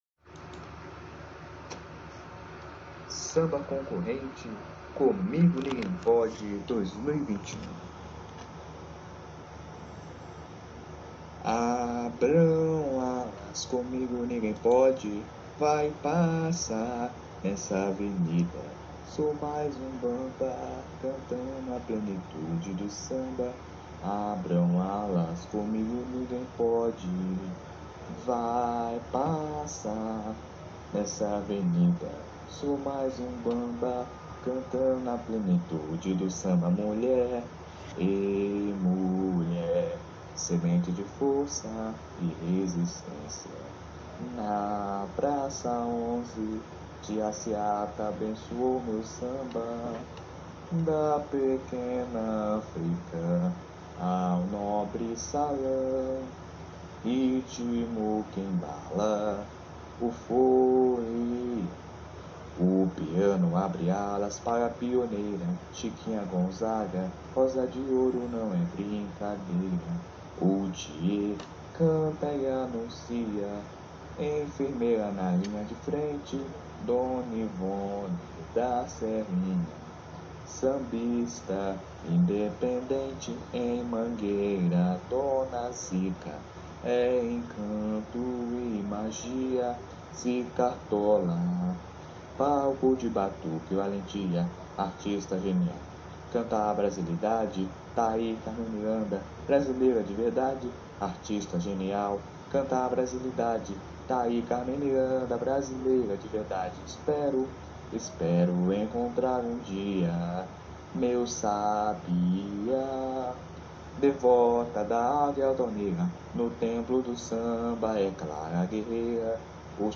Samba  07